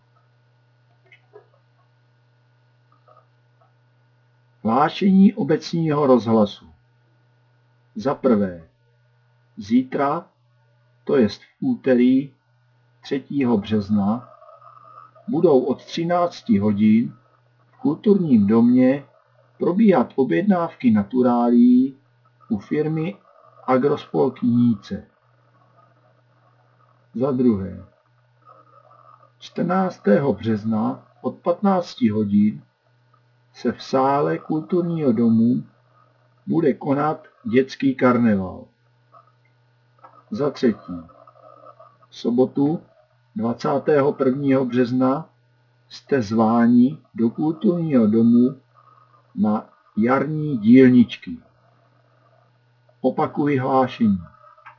Aktuálně Zobrazit starší aktuality Kalendář akcí Mapa akcí Hlášení obecního rozhlasu Zobrazit starší rozhlasy Chcete hlášení obecního rozhlasu zasílat na e-mail?
Hlášení 2.3.2026 (naturálie, dětský karneval)